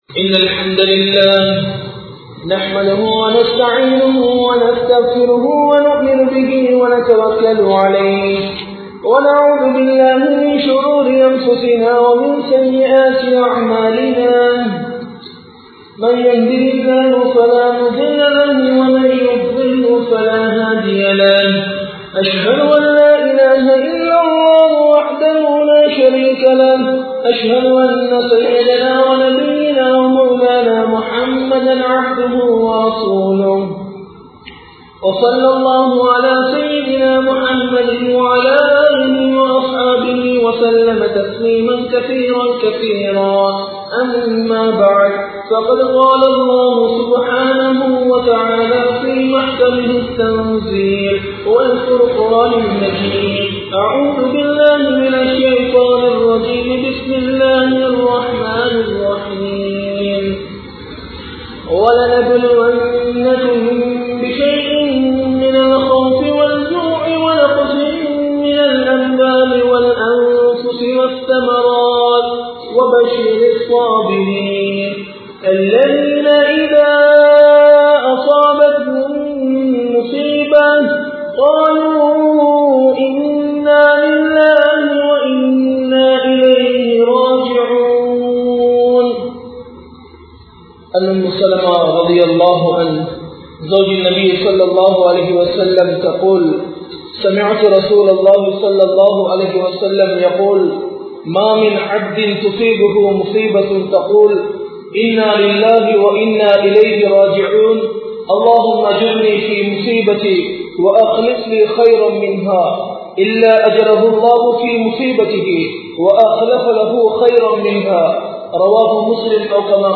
Sirappana Duakkal! (சிறப்பான துஆக்கள்!) | Audio Bayans | All Ceylon Muslim Youth Community | Addalaichenai
Mallawapitiya Jumua Masjidh